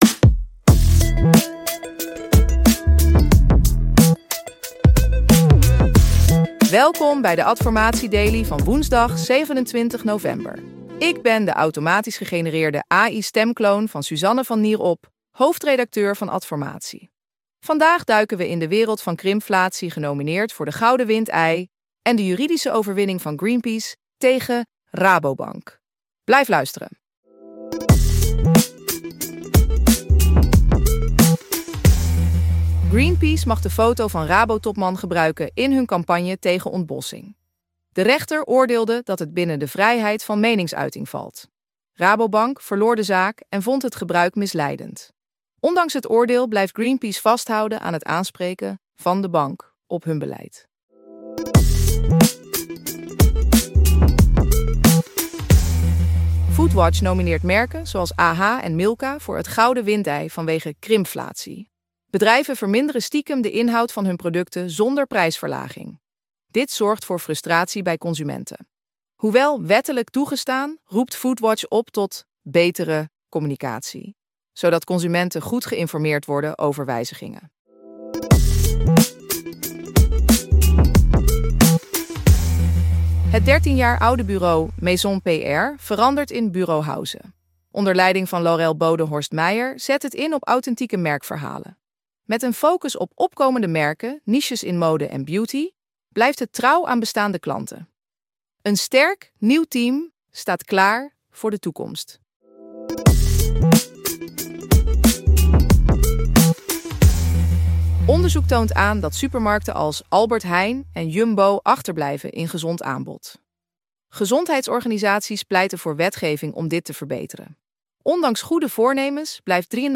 De Adformatie Daily wordt dagelijks automatisch gegenereerd met AI door EchoPod.